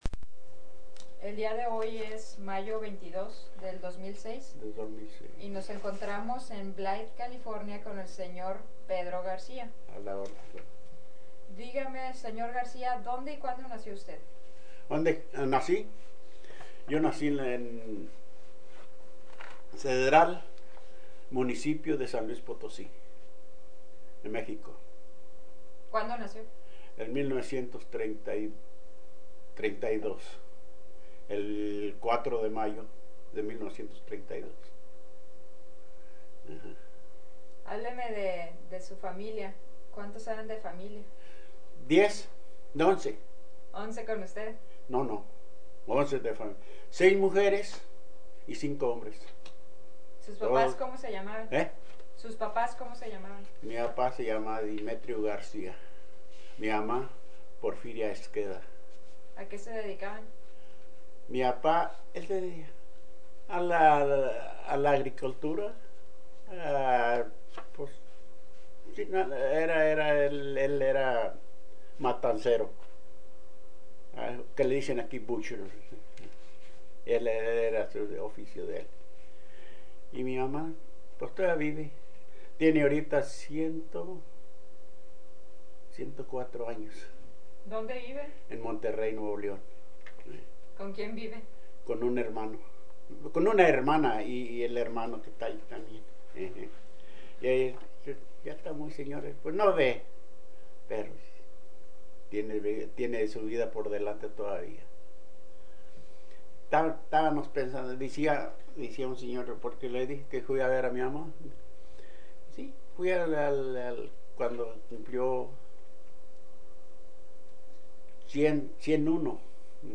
Summary of Interview
Original Format Mini DIsc